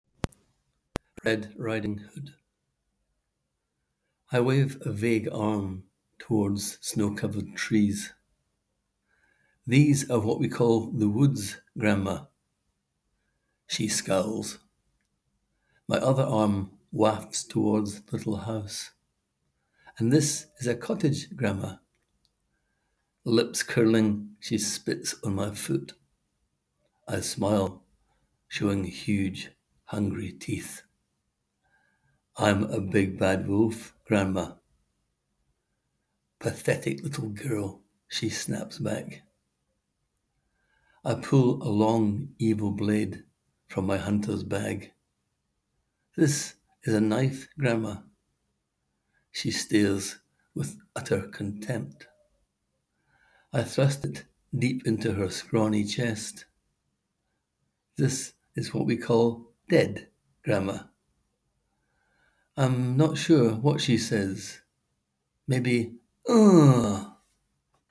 Click here to hear the author read his words: